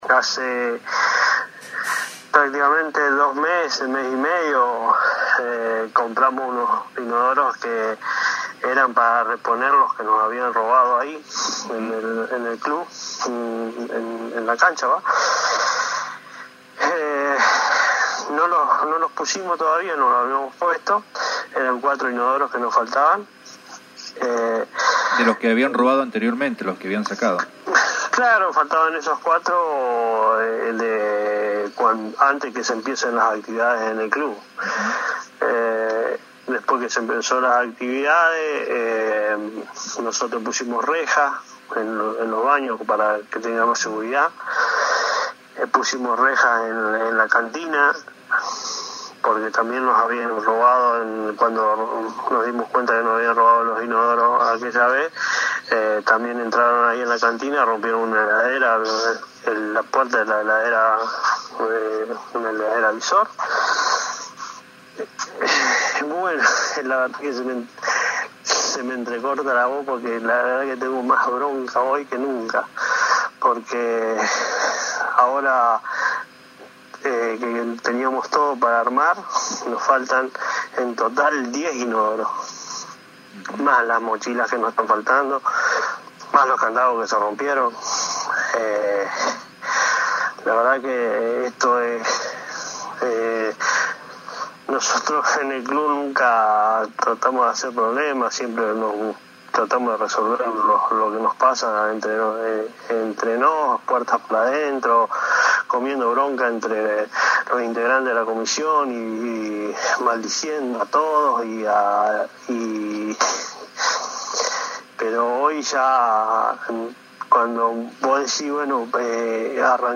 Audio gentileza FM 89.9 Auténtica de Villa Minetti.